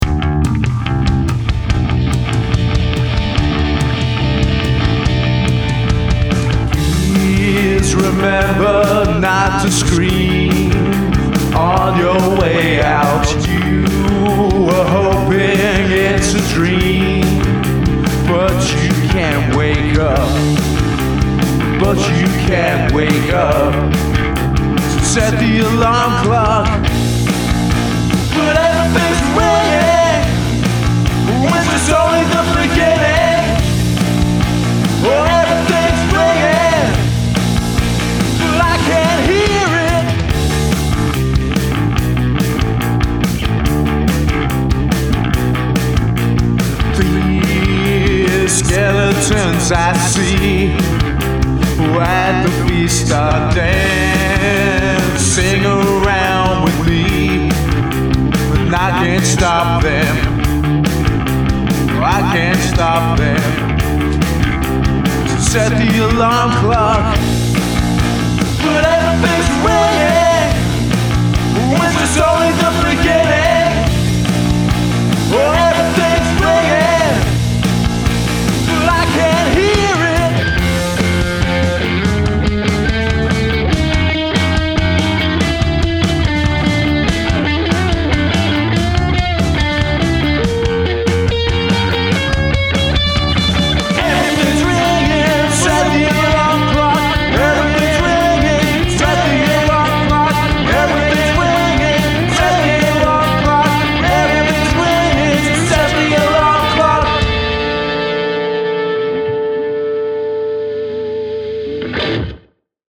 But here we go with some garage-y punk. A cheery song about feeling trapped in permacrisis mode.
Very cool, I love how you have given the bass the centre stage instead of the guitars. Loved the frantic guitar soloing at the end.
I dig the chorus and the dissonant guitar solo.
This is very cool and danceable! That dissonant guitar solo brings out the crisis vibe